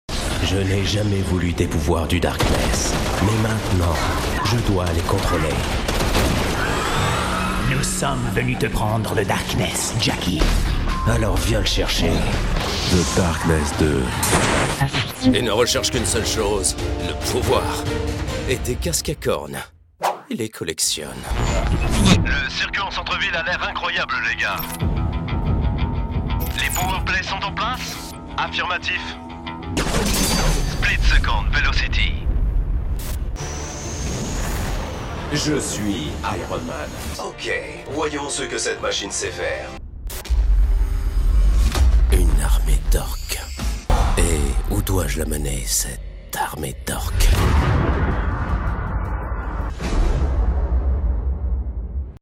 Male
Assured, Character, Confident, Conversational, Cool, Corporate, Deep, Engaging, Friendly, Gravitas, Natural, Reassuring, Sarcastic, Smooth, Soft, Versatile, Warm
documentary.mp3
Microphone: Neumann TLM103